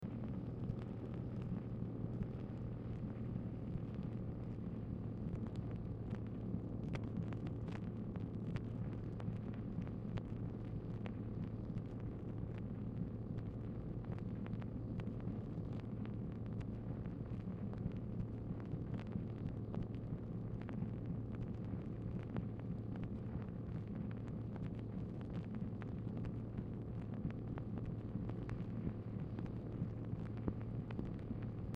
Telephone conversation # 6749, sound recording, MACHINE NOISE, 1/28/1965, time unknown | Discover LBJ
Format Dictation belt
Speaker 2 MACHINE NOISE